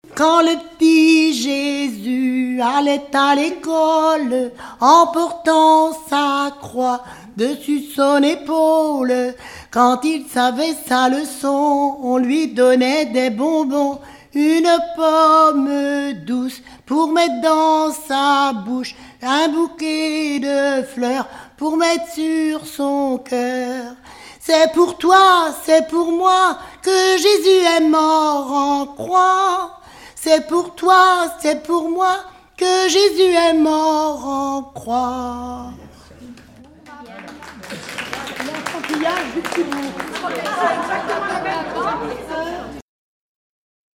Mémoires et Patrimoines vivants - RaddO est une base de données d'archives iconographiques et sonores.
enfantine : prière, cantique
Pièce musicale inédite